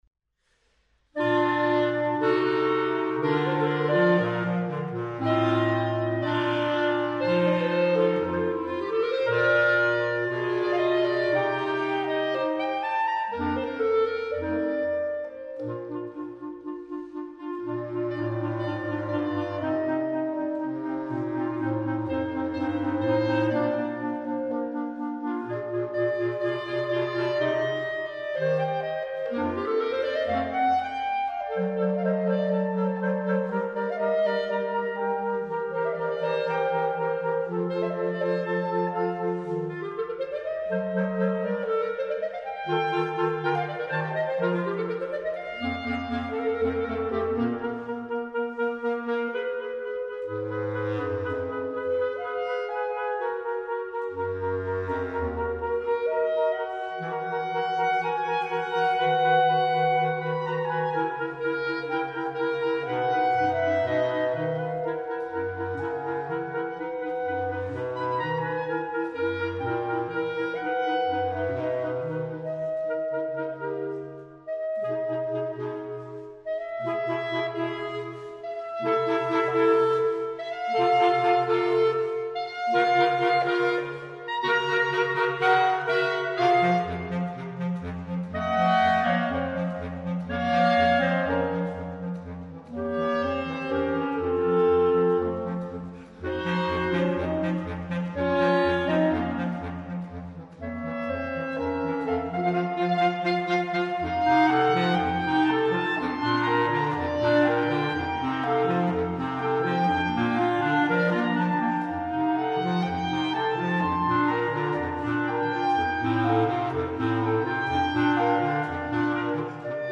DUE QUARTETTI PER CLARINETTI
I due movimenti di Quartetto per quattro clarinetti sono custoditi presso l’Accademia Filarmonica di Bologna e sono quì pubblicati per la prima volta.